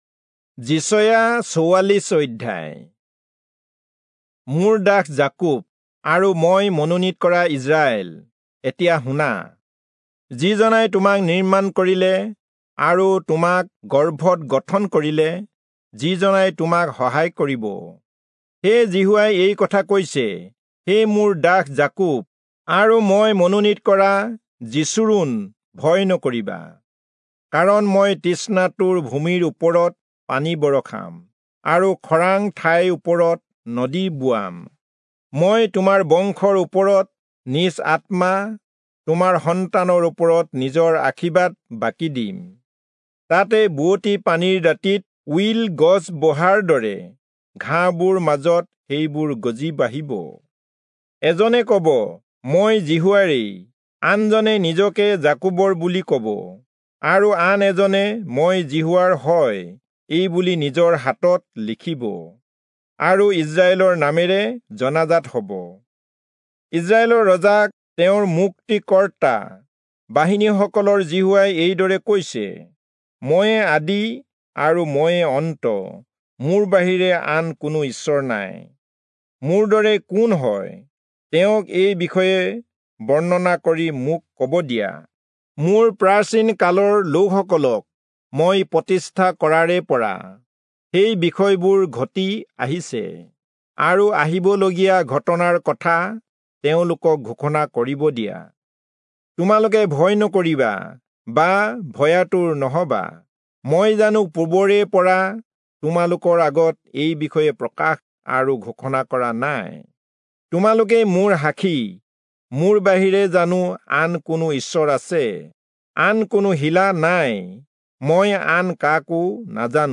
Assamese Audio Bible - Isaiah 55 in Ervmr bible version